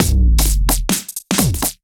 OTG_Kit 1_HeavySwing_130-B.wav